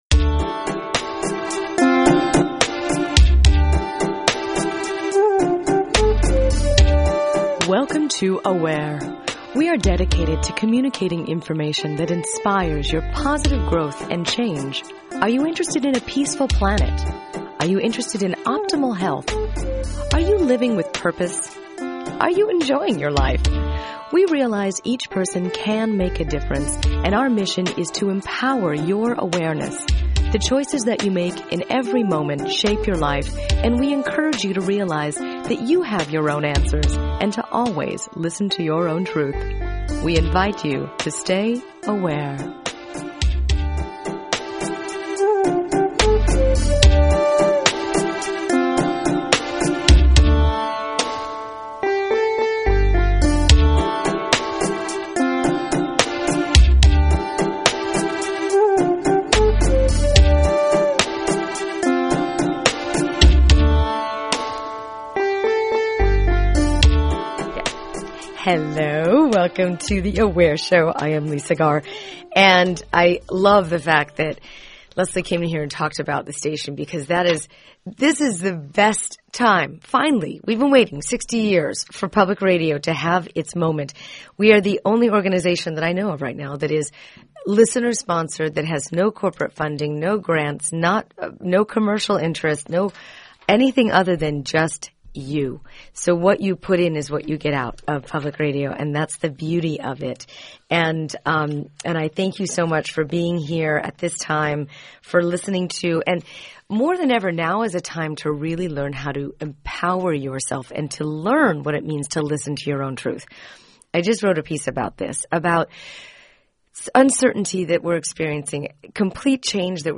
If you’re looking for an event you can attend where kindred spirits are thriving, inspiring and evolving, then listen to this interview!